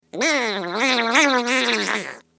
دانلود صدای حشره 9 از ساعد نیوز با لینک مستقیم و کیفیت بالا
جلوه های صوتی